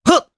Evan-Vox_Attack2_jp.wav